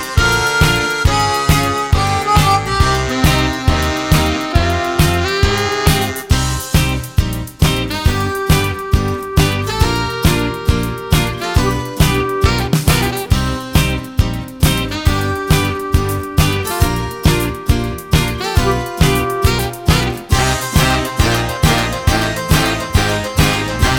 no Backing Vocals Ska 3:01 Buy £1.50